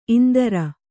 ♪ indara